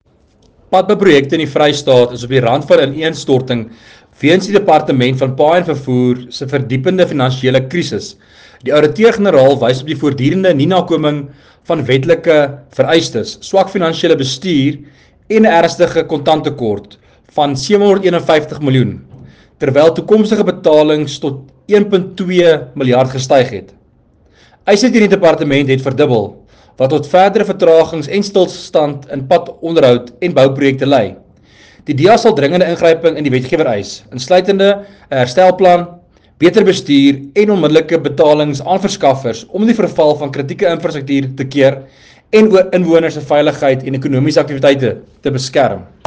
Afrikaans soundbite by Werner Pretorius MPL with images here and here, and a summary of the AG report here